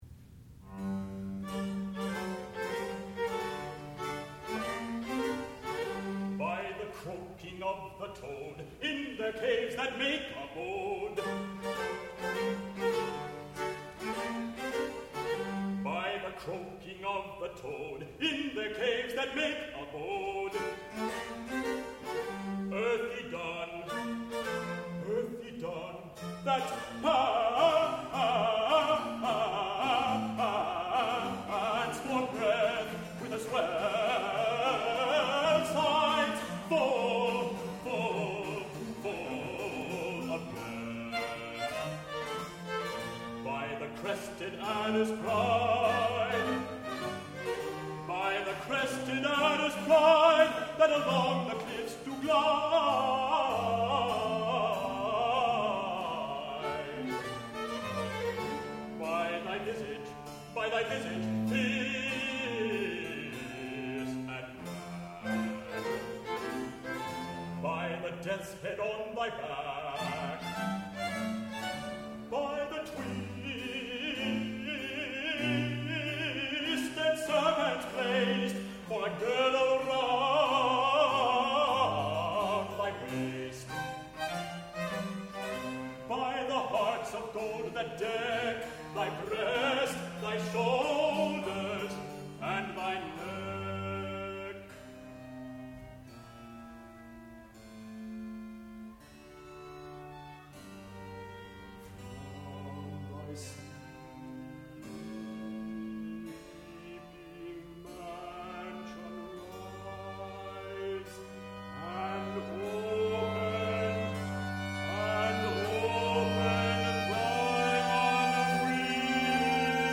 sound recording-musical
classical music
baritone
contrabass
harpsichord